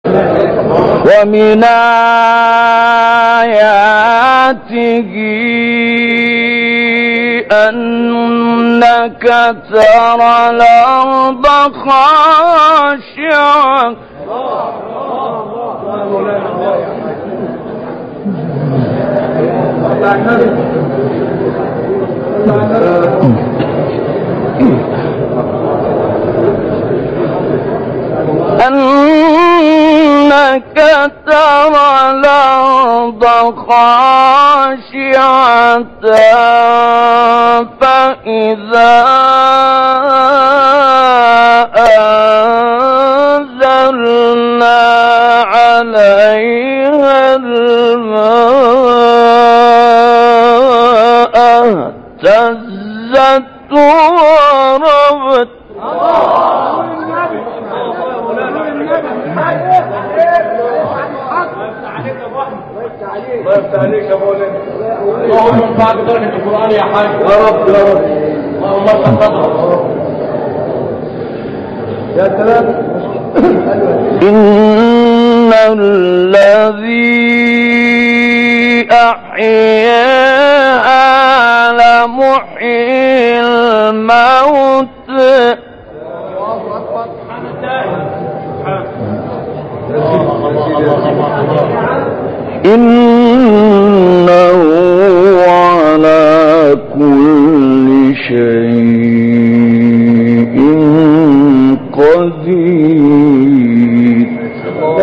تلاوت آیه‌های بهاری/11